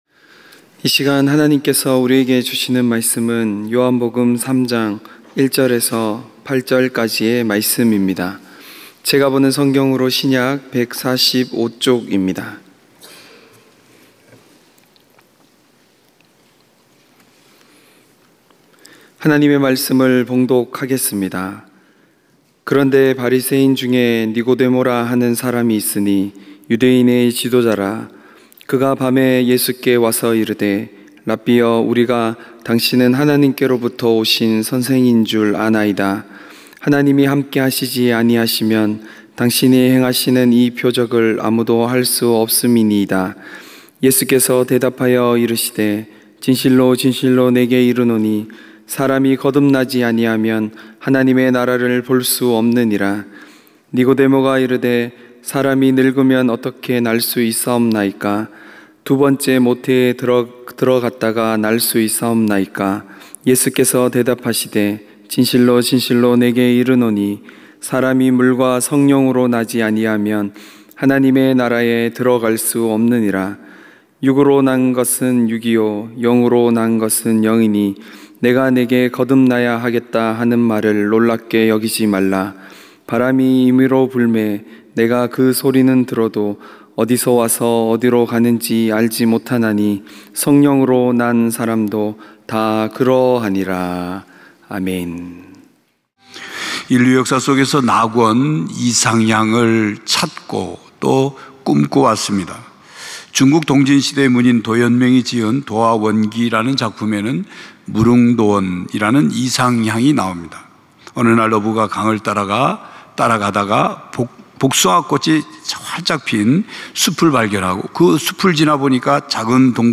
주일 청년예배 - 초대받은 손님